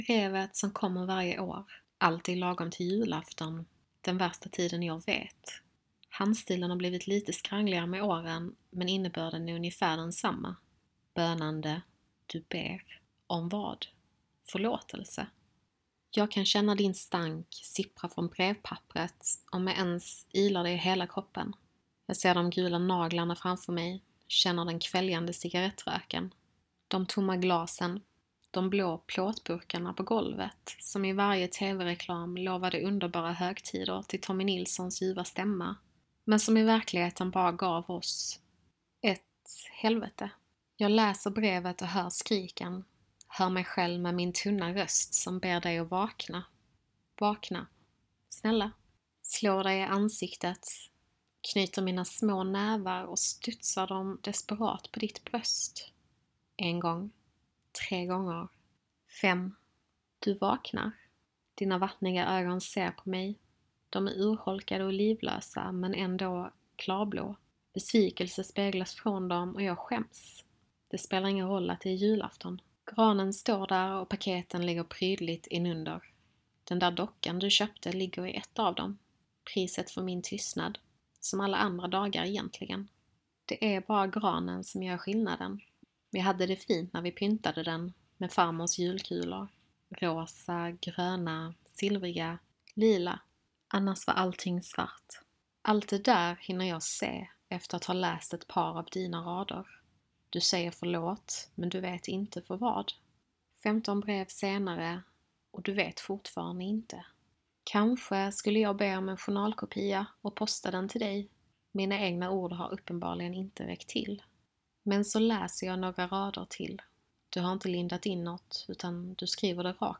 I denna novell skildrar jag ett vuxet barns mörka tankar när hen tänker tillbaka på sin barndom. I ljudnovellen nedan har jag, under några minuters provlyssning, försökt förmedla den känsla som jag vill att Brevet ska ge dig som läsare - i detta med mig som själv berättare.